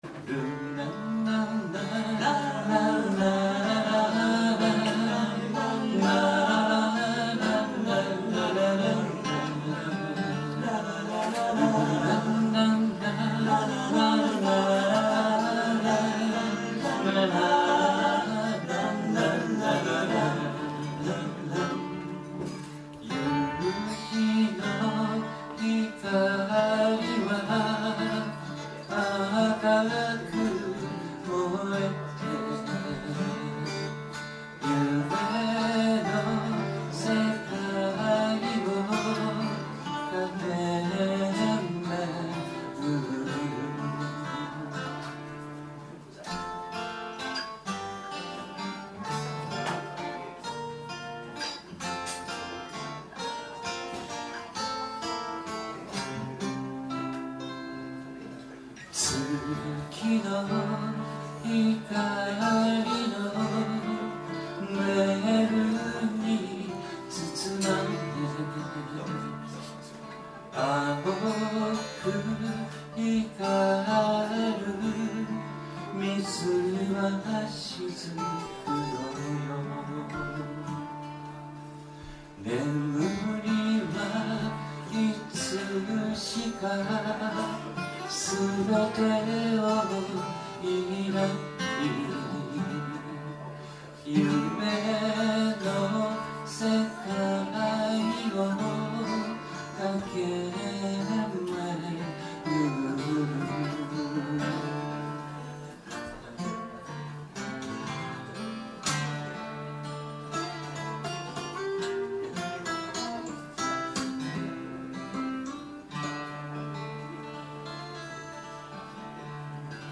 東京都府中市　「ライブシアターFlight」
社会人バンド・コンベンション